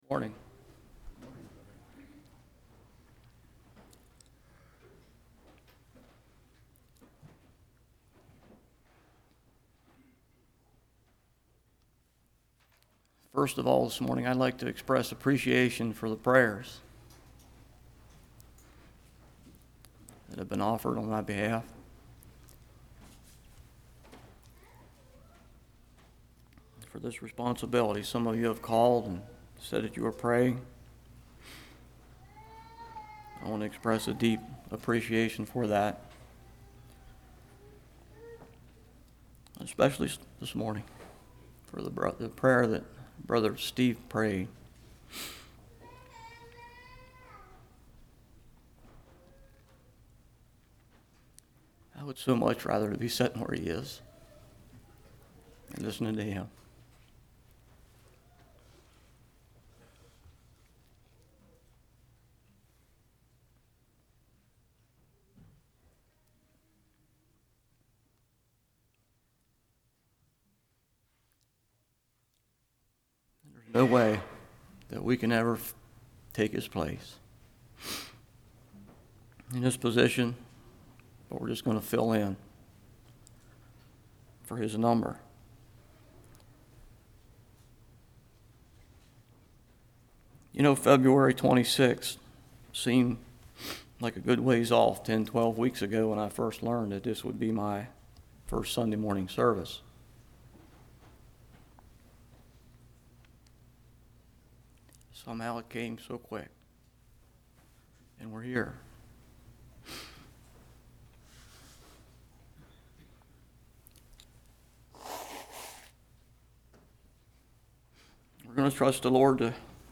Hebrews 2:1-18 Service Type: Morning How much value salvation?